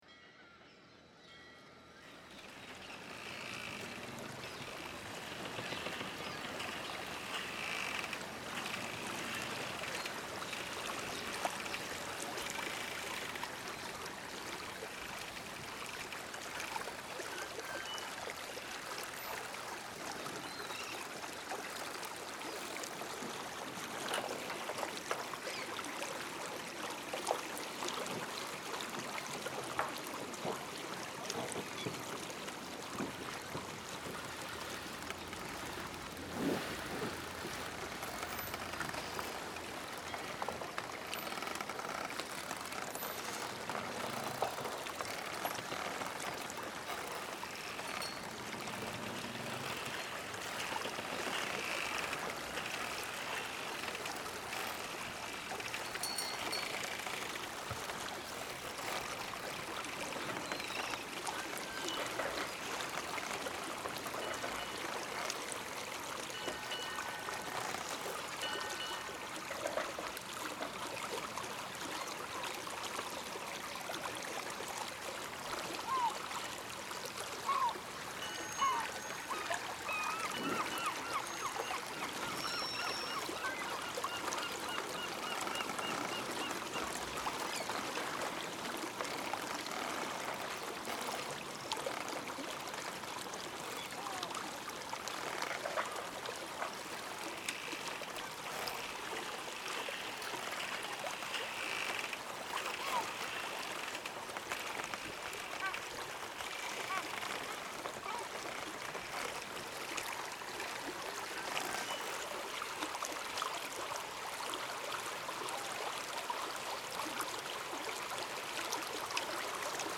A Harbour Soundscape, a northern European city, around 100 years ago.
MarineSoundscapeAmsterdam.mp3